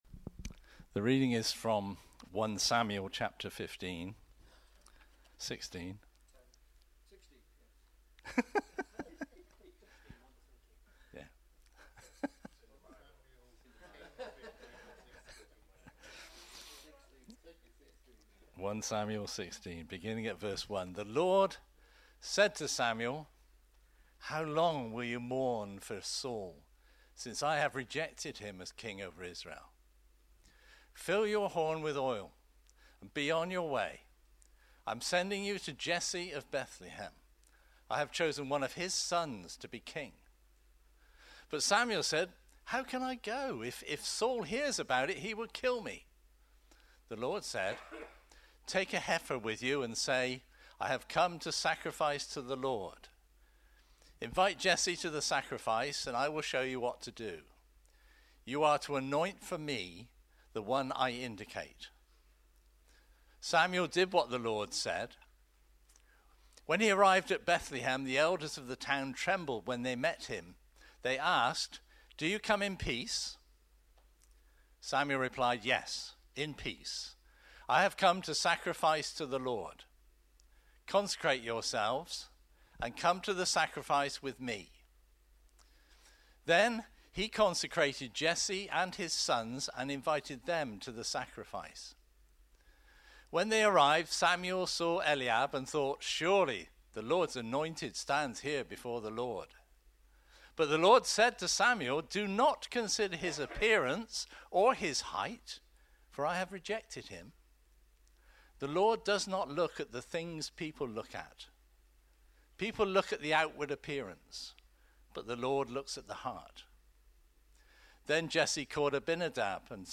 Here is the link to the bible reading being read at the beginning 1 Sam 16:1-16